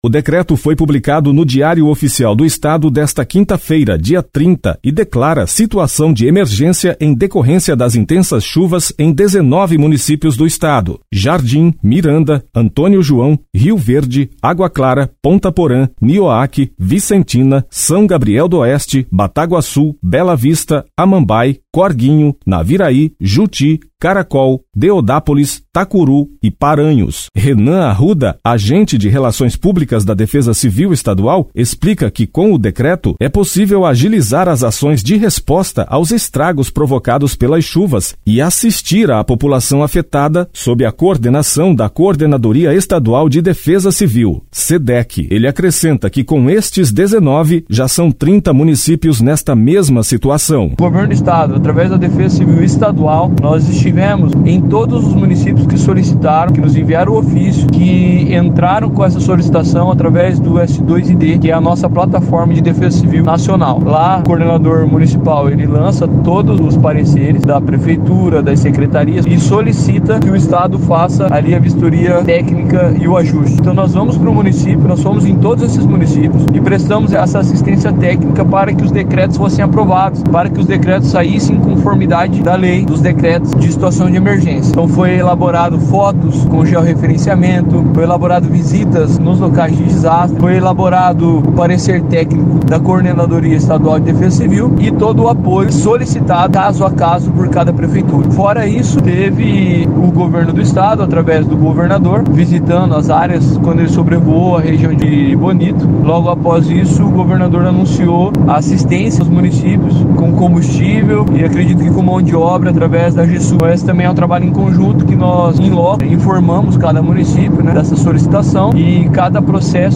Entenda os detalhes na reportagem